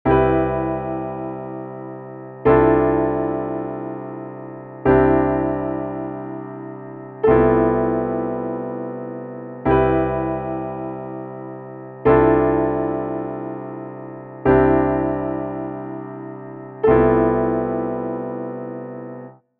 こちらは「安定感が落ちてTらしくなくなる」ことを逆手に取って、Cのコードだけを下地にしながら安定→不安定の微かな揺れを楽しむという上品なコード進行で、これは全然成立していますよね。